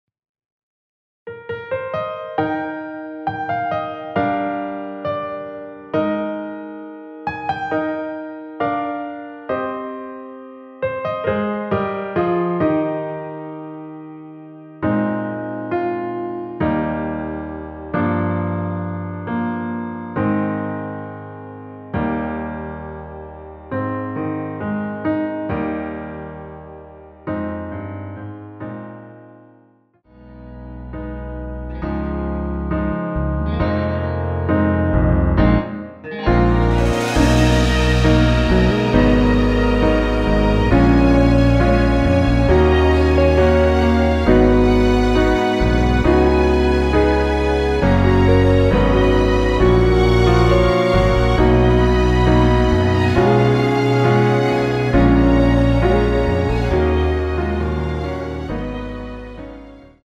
원키에서(+1)올린 MR입니다.
Eb
앞부분30초, 뒷부분30초씩 편집해서 올려 드리고 있습니다.
중간에 음이 끈어지고 다시 나오는 이유는